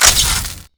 damaged.wav